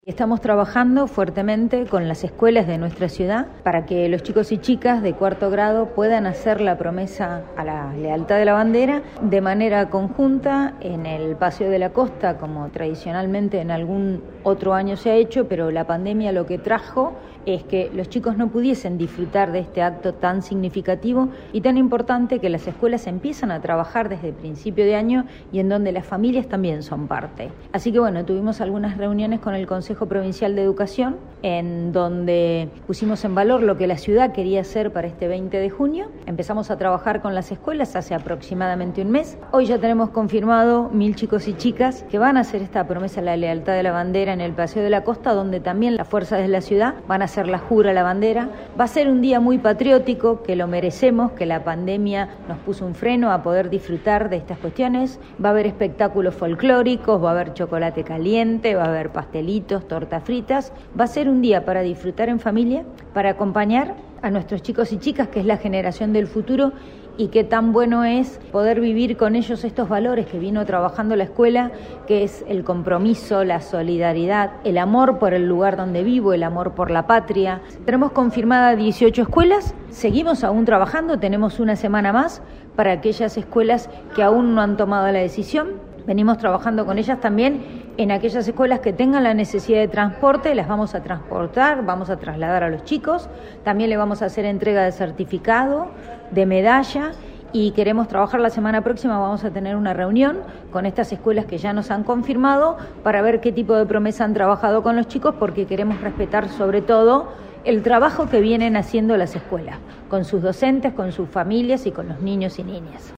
María Pasqualini, secretaria de Capacitación y Empleo.